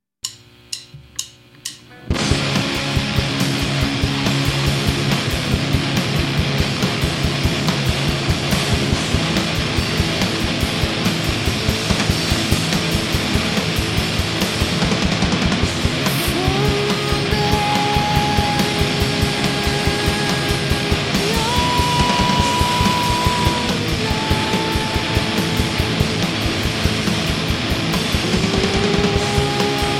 Psicadélia exacerbada, sem pretensões.